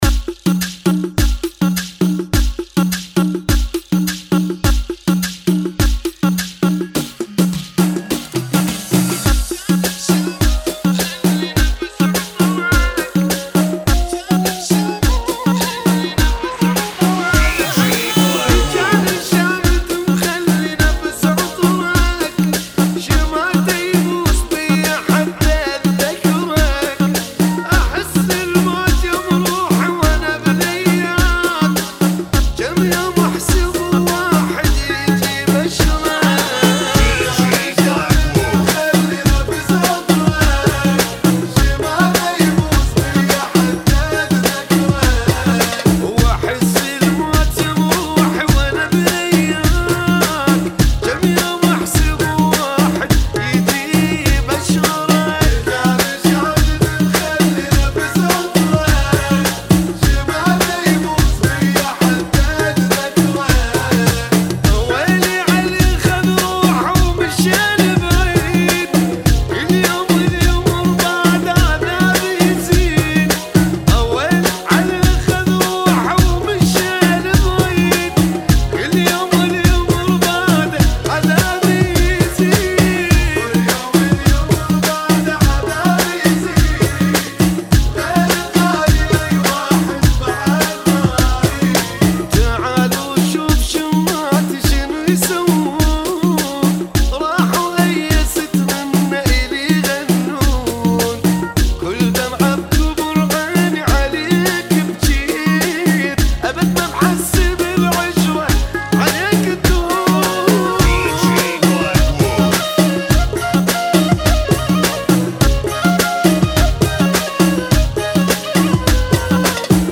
94 Bpm